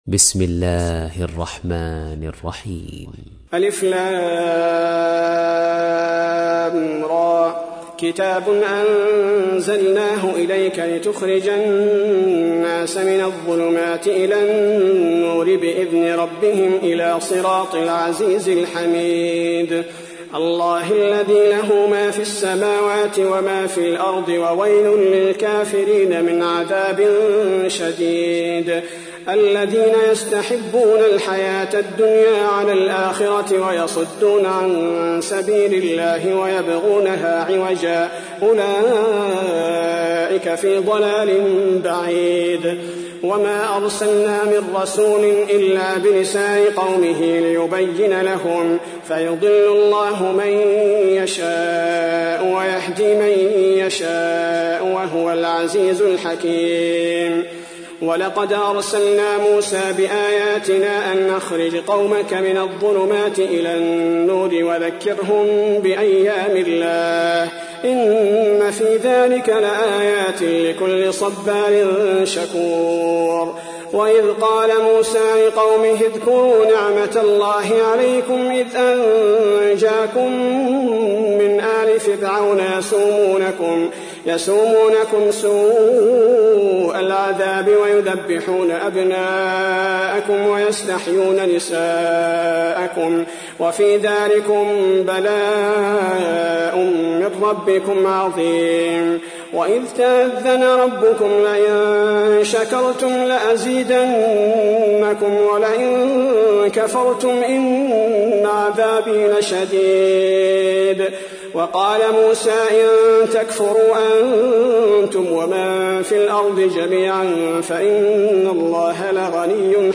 تحميل : 14. سورة إبراهيم / القارئ صلاح البدير / القرآن الكريم / موقع يا حسين